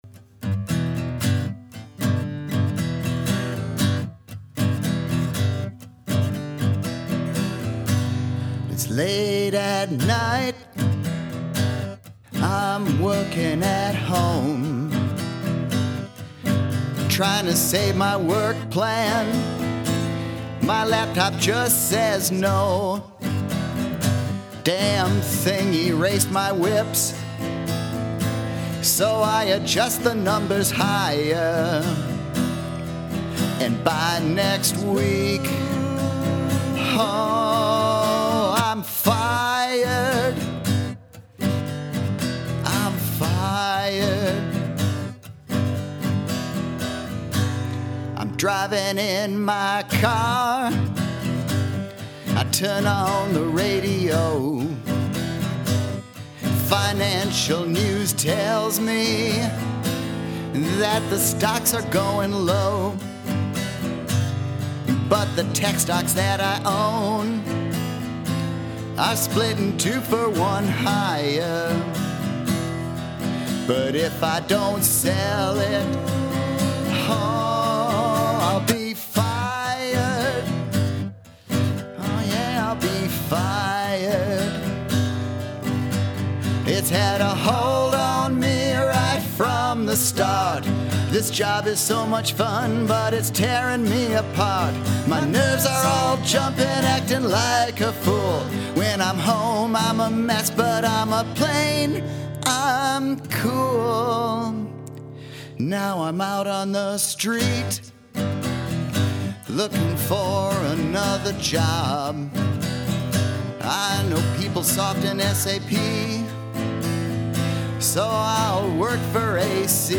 Lastly, here’s some old CPI/PW music we had during the party, along with the words to the song we sang.